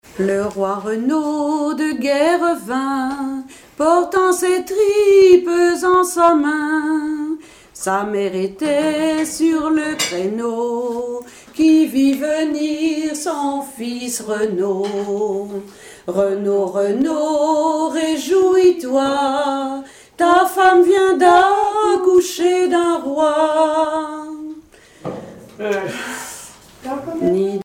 Mémoires et Patrimoines vivants - RaddO est une base de données d'archives iconographiques et sonores.
Genre strophique
Chansons et commentaires
Pièce musicale inédite